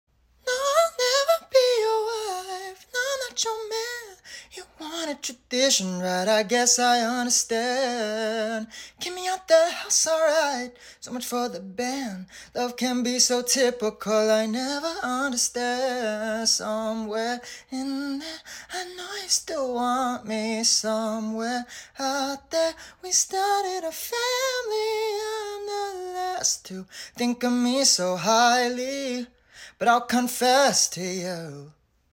sounds kinda scary acapella but sound effects free download